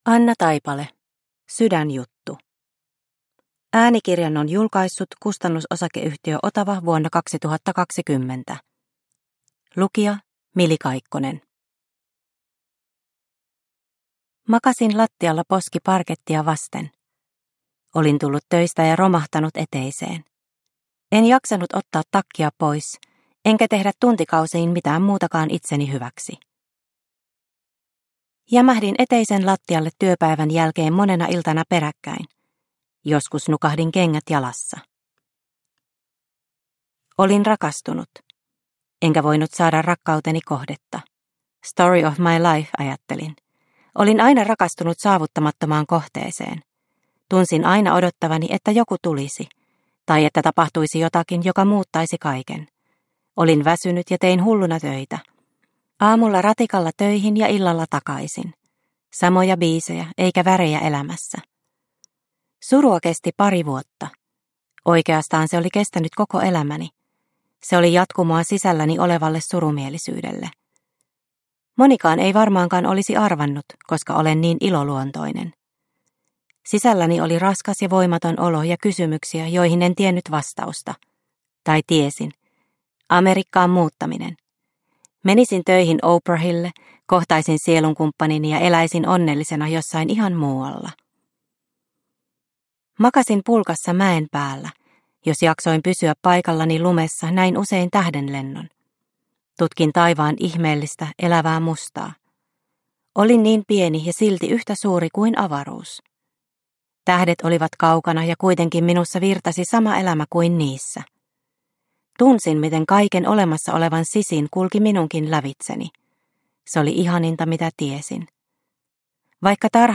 Sydänjuttu – Ljudbok – Laddas ner